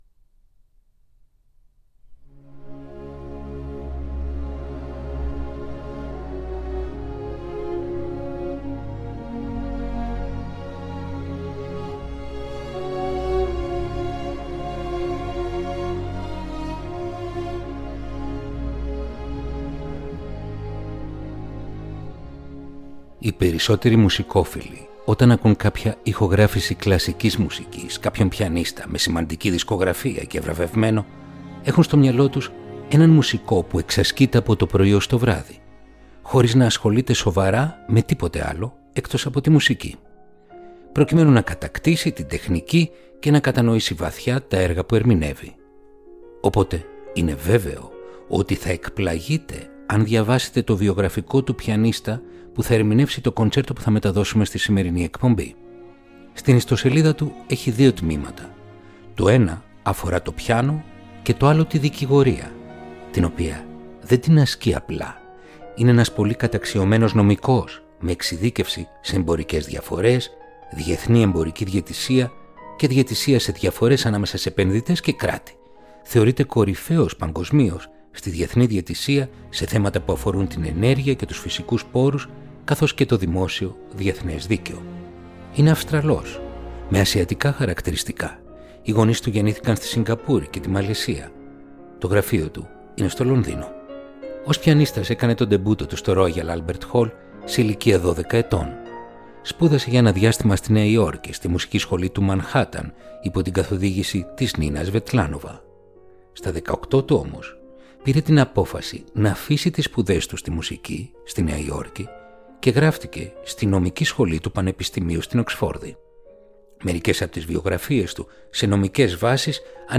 Ρομαντικά κοντσέρτα για πιάνο – Επεισόδιο 36ο